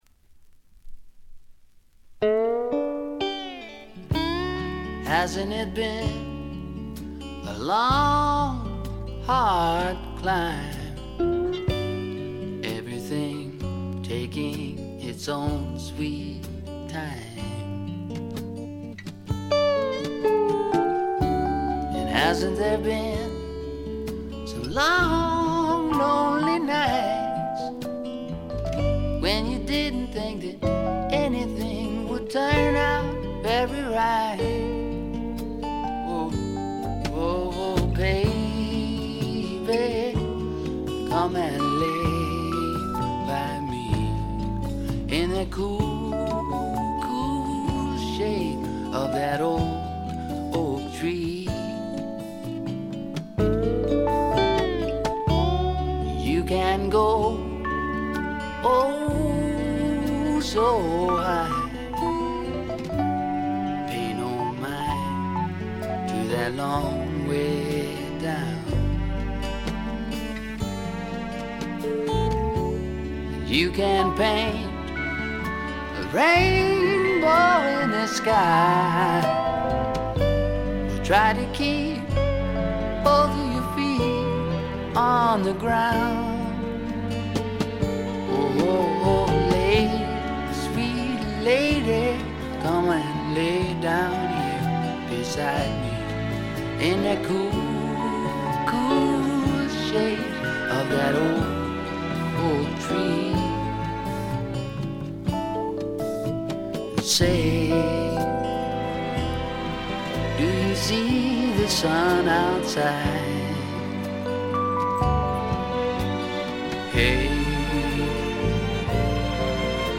ごくわずかなノイズ感のみ。
独特のしゃがれた渋いヴォーカルで、スワンプ本線からメローグルーヴ系までをこなします。
試聴曲は現品からの取り込み音源です。